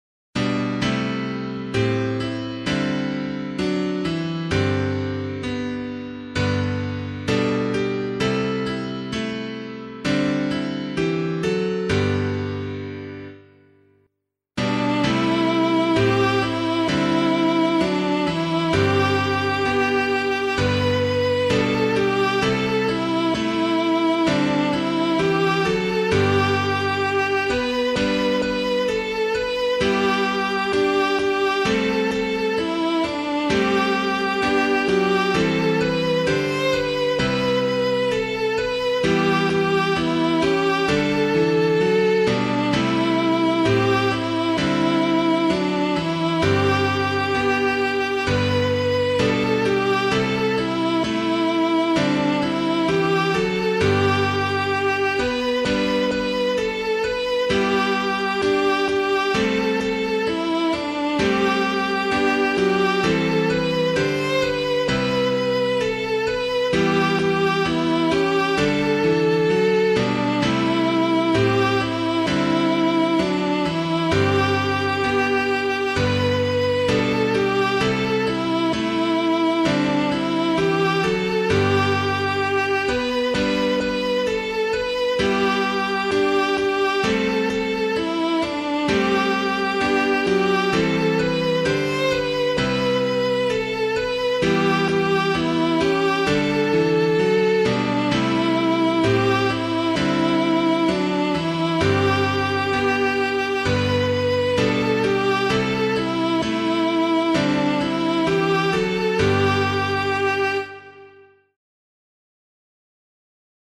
Hymn suitable for Catholic liturgy